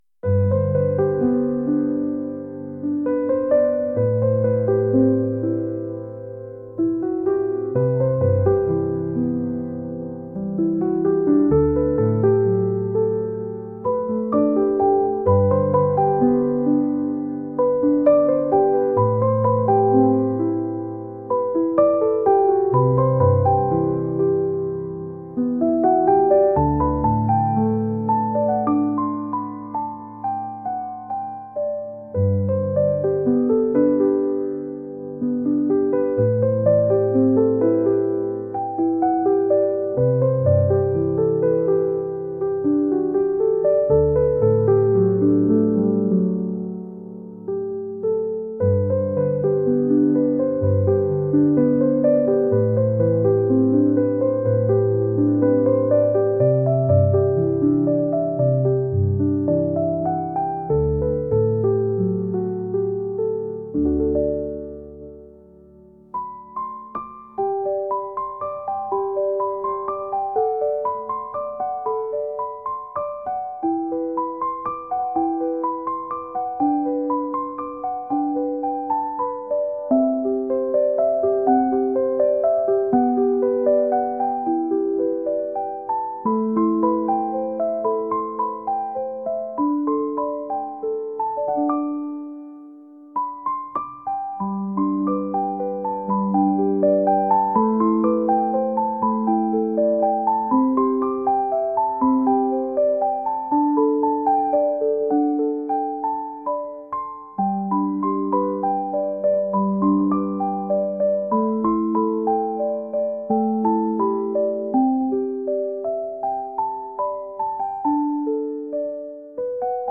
ゆったりとした穏やかなピアノ曲です。